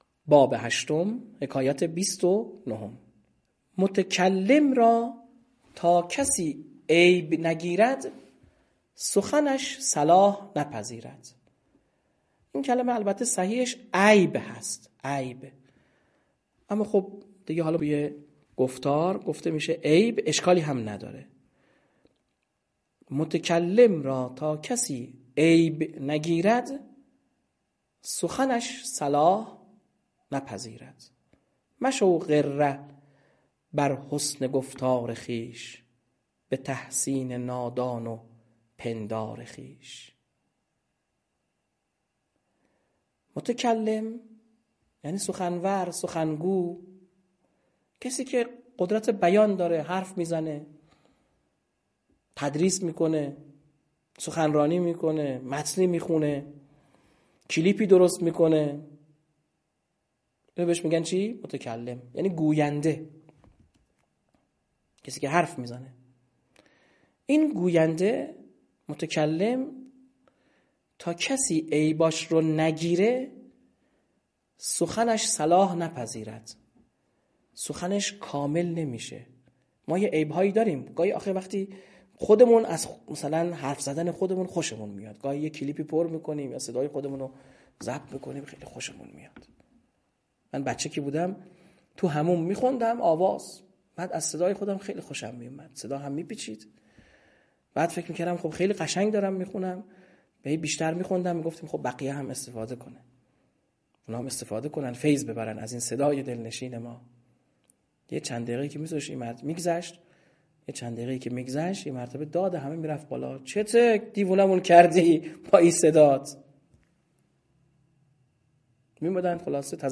فایل صوتی شرح غزل 14 حافظِ قرآن